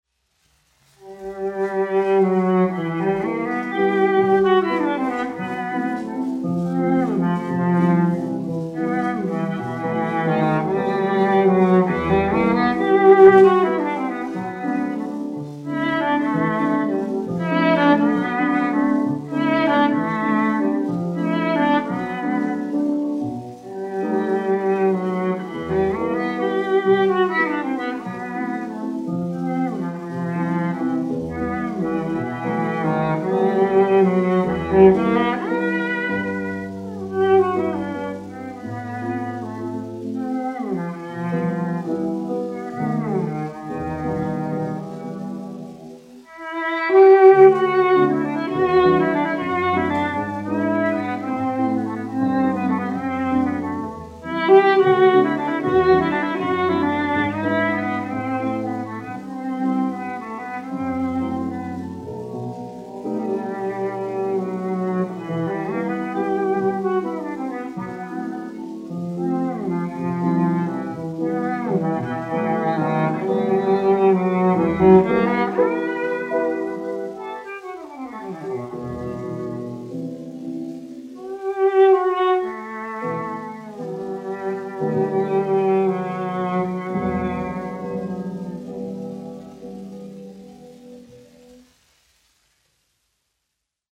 1 skpl. : analogs, 78 apgr/min, mono ; 25 cm
Čella un klavieru mūzika, aranžējumi
Latvijas vēsturiskie šellaka skaņuplašu ieraksti (Kolekcija)